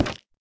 Minecraft Version Minecraft Version snapshot Latest Release | Latest Snapshot snapshot / assets / minecraft / sounds / block / nether_wood_trapdoor / toggle3.ogg Compare With Compare With Latest Release | Latest Snapshot